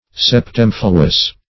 Search Result for " septemfluous" : The Collaborative International Dictionary of English v.0.48: Septemfluous \Sep*tem"flu*ous\, a.[L. septemfluus; septem seven + fluere to flow.] Flowing sevenfold; divided into seven streams or currents.